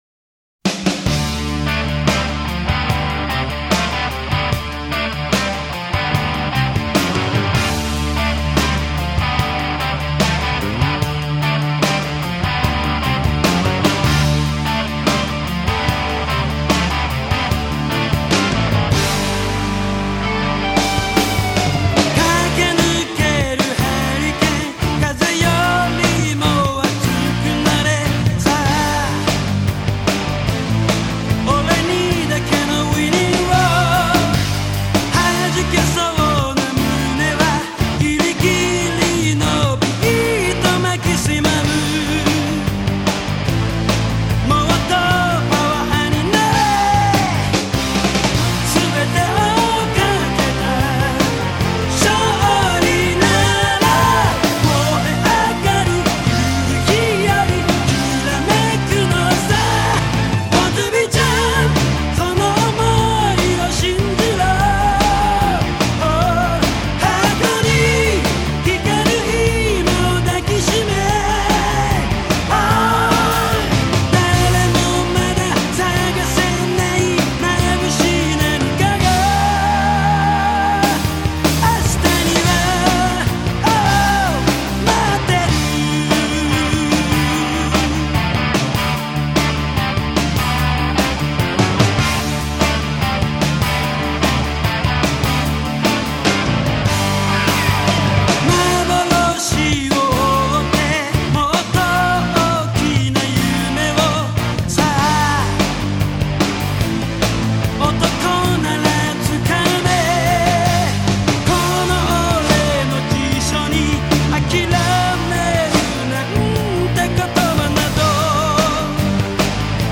(오케스트라 사운드가 거의 사용되지 않았으며 기계적인 사운드에만 의존.)
역시 노래부르는 보컬이 맘에 안듭니다....